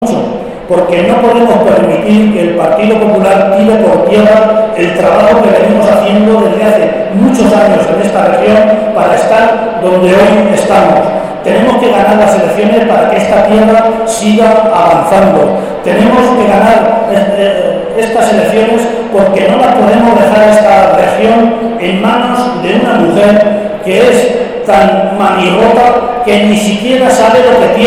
Cerca de 600 personas han acudido a la comida de Navidad que el PSOE de ha ofrecido en la capital, sin embargo, tres acto de similares características se ha celebrado también este fin de semana en Tarancón, San Clemente y Quintanar del Rey.